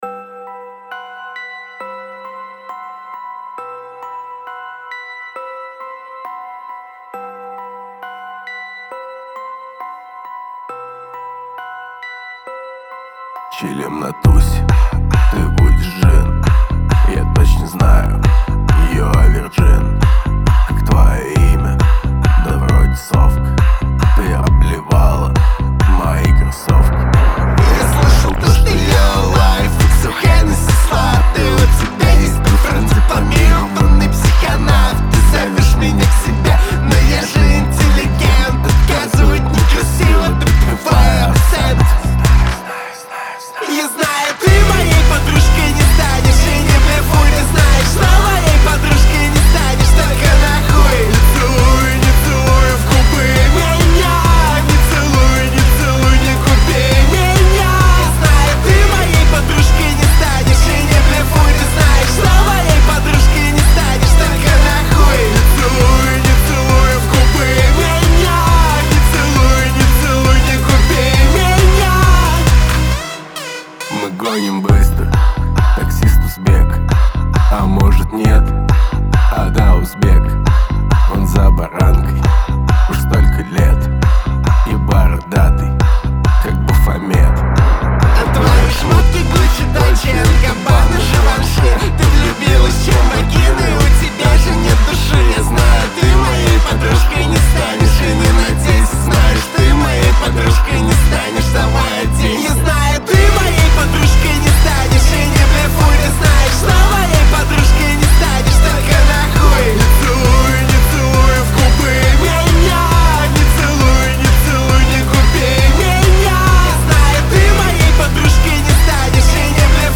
яркая и мелодичная композиция